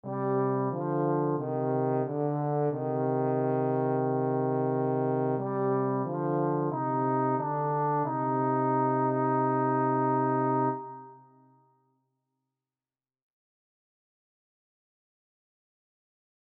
And this is the sample score you provided but with Miroslav Philharmonik instruments; without Notion 3 Reverb; but with full panning rather than the selected panning . . .
If you listen carefully to the second version, you will hear that the "dry" instruments actually have reverberation, which is due to the instruments being played and recorded in a concert hall (specifically, the Rudolfinum Dvorák Concert Hall)
FD-Octave-Miroslav-Philharmonik-No-Reverb-Full-Panning.mp3